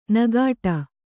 ♪ nagāṭa